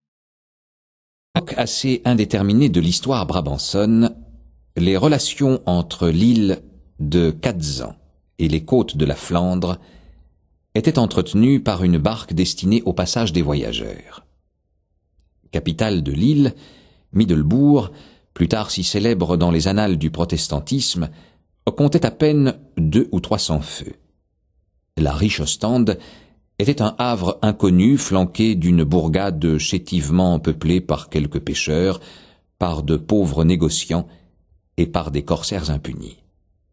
Click for an excerpt - Jésus-Christ en Flandre de Honoré de Balzac